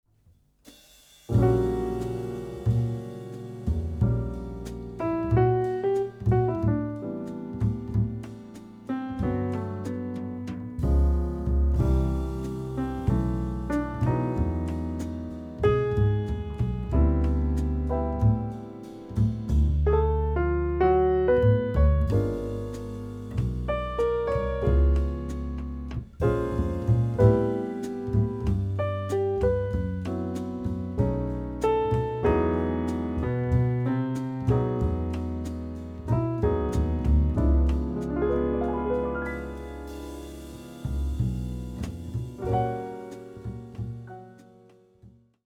ジャズシーンのトップランナーと織りなす 耽美で幻想的なオリジナル作品集。